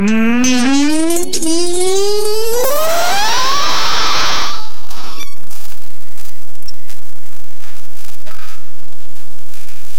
Give me a transition between two different songs like tic tic
give-me-a-transition-betw-3hkmjlll.wav